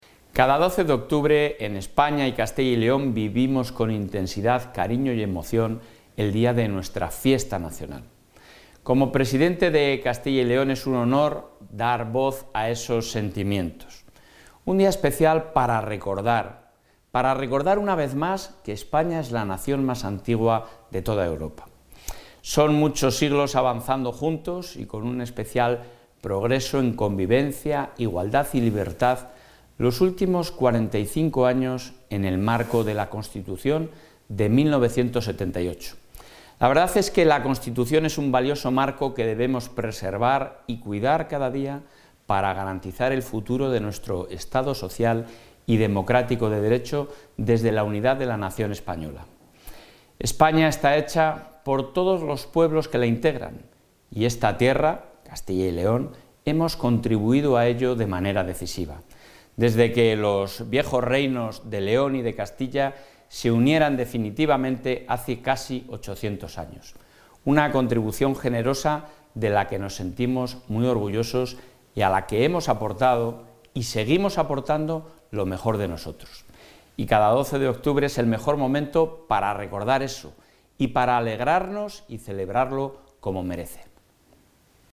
Mensaje del presidente de la Junta.